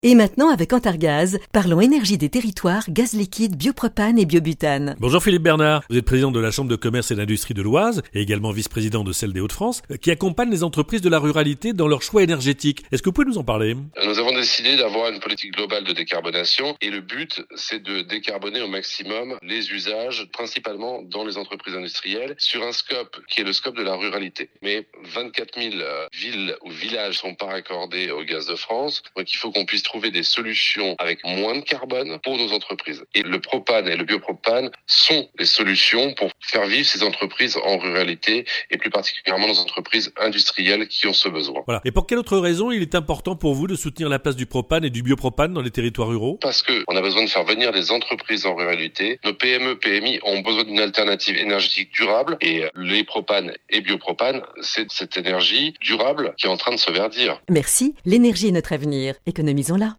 Nos spots radio autour de la thématique de la décarbonation des territoires